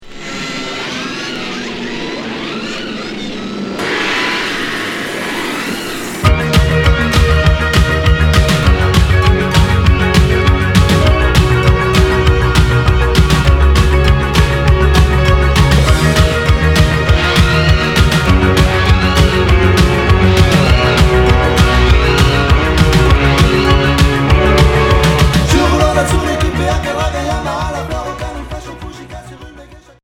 New wave Deuxième 45t retour à l'accueil